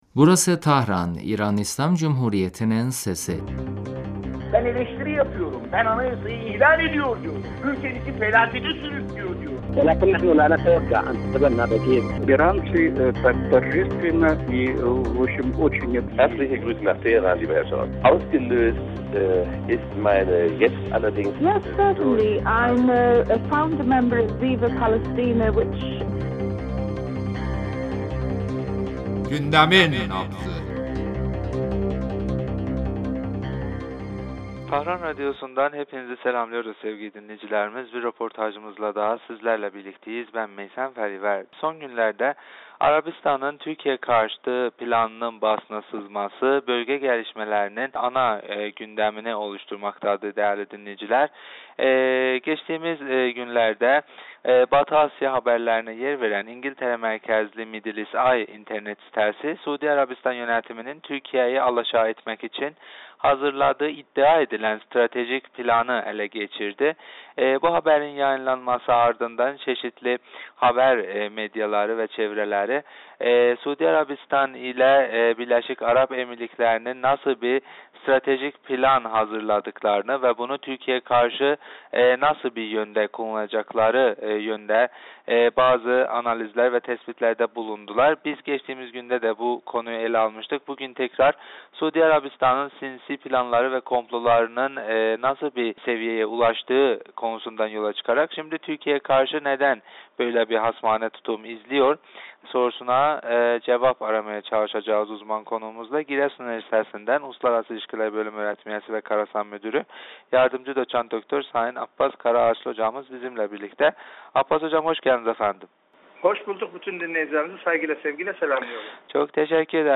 radyomuza verdiği demecinde Suud rejiminin Türkiye'ye karşı hasmane politikaları ve yeni stratejisi üzerinde görüşlerini bizimle paylaştı.